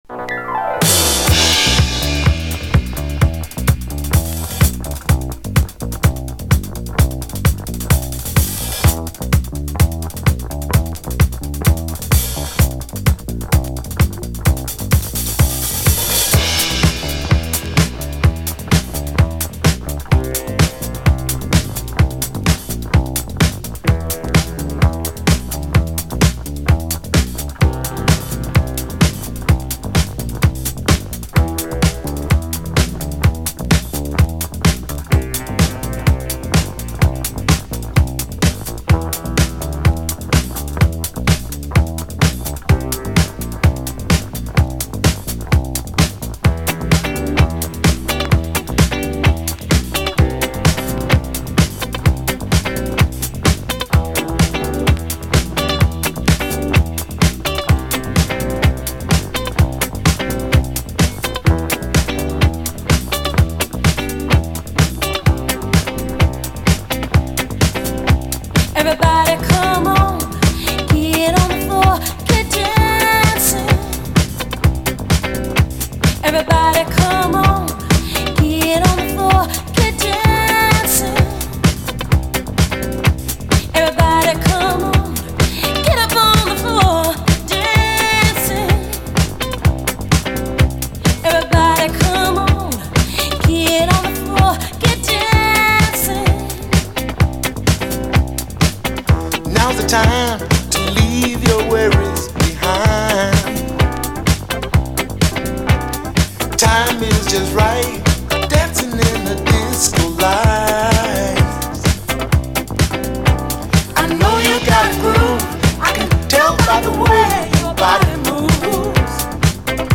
DISCO
妖艶ディスコ・クラシック！